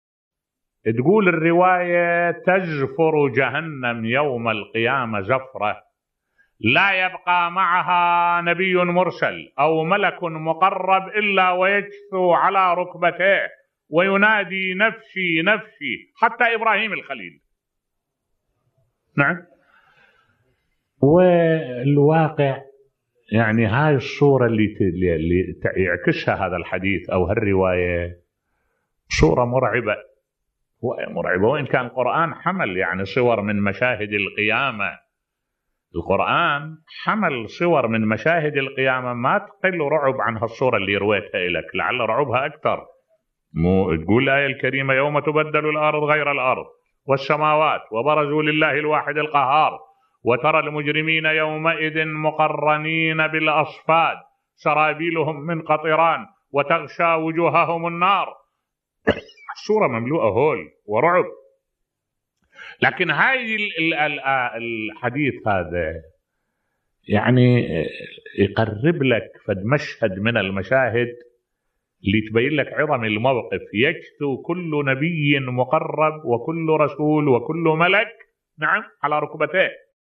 ملف صوتی صفة جهنم والفزع الأكبر بصوت الشيخ الدكتور أحمد الوائلي